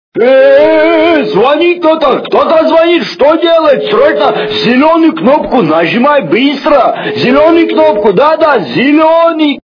» Звуки » Люди фразы » Голос - Звонит кто-то жми зелёную кнопку
При прослушивании Голос - Звонит кто-то жми зелёную кнопку качество понижено и присутствуют гудки.
Звук Голос - Звонит кто-то жми зелёную кнопку